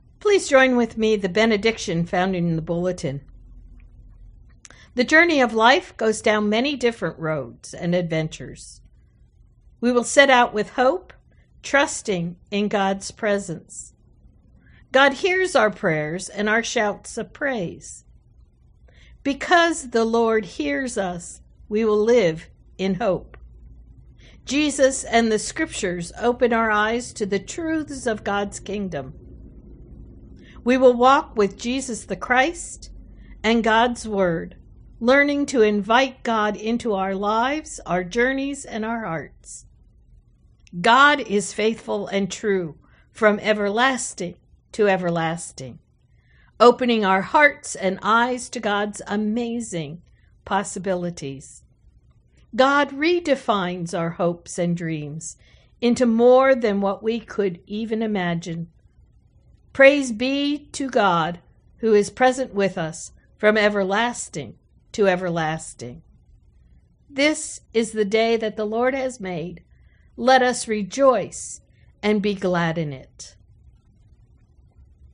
Closing Hymn: Precious Lord, Take My Hand